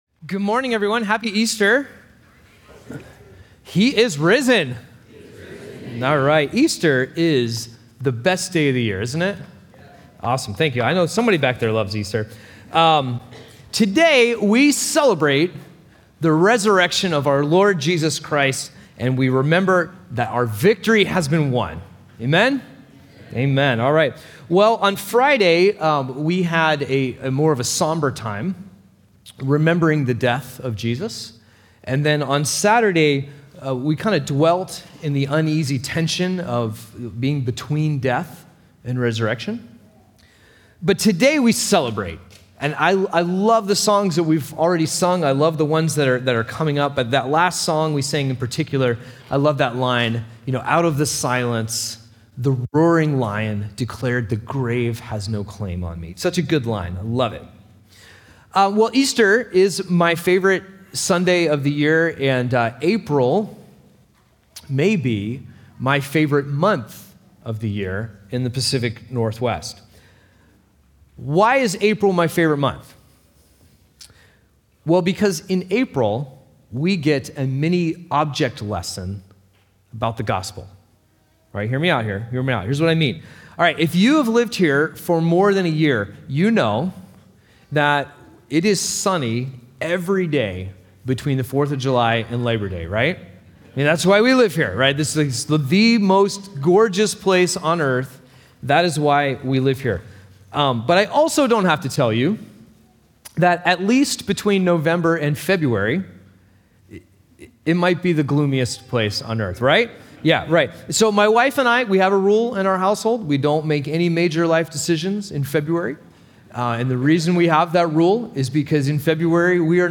Happy Easter!